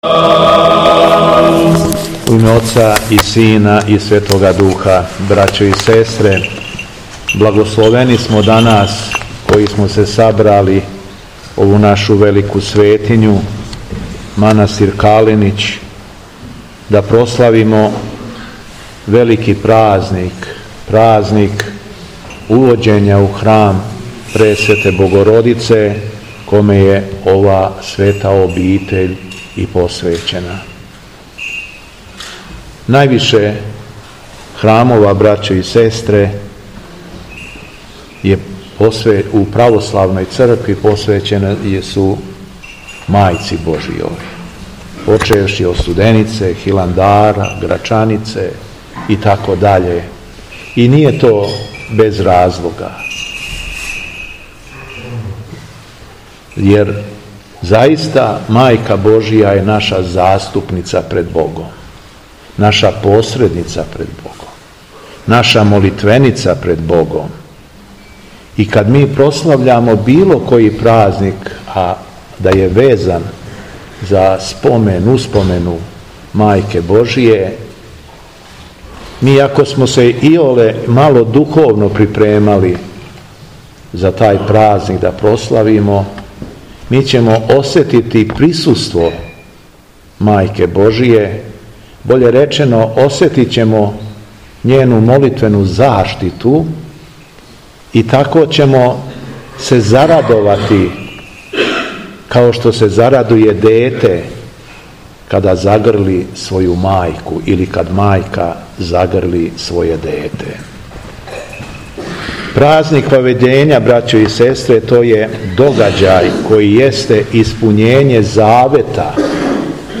Беседа Његовог Преосвештенства Епископа шумадијског г. Јована
По прочитаном Јеванђељу Валдика Јован се обратио својом беседом верном народу: